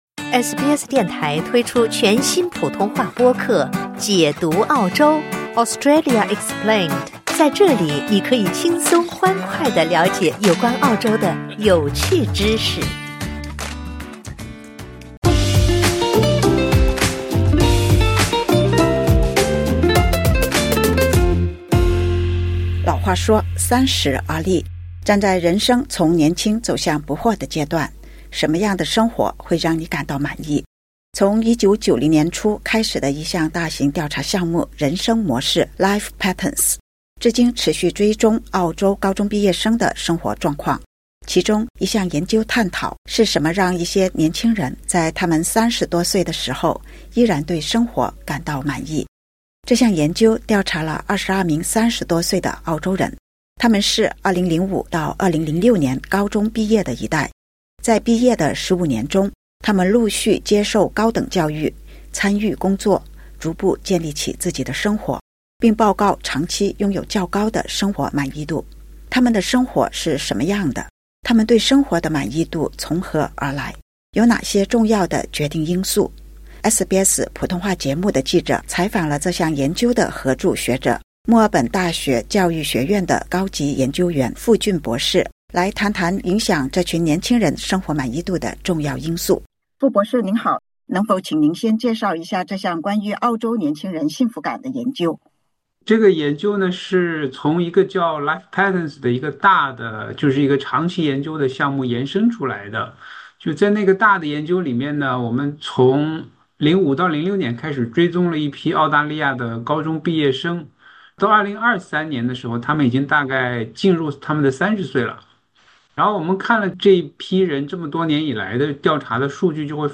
这些声音来自受访的澳大利亚年轻人，他们30岁出头，分享了自己认为重要的事物和促使自己长期对生活感到满意的原因（点击播客，收听详情）。